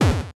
disappear.wav